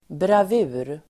Ladda ner uttalet
Uttal: [brav'u:r]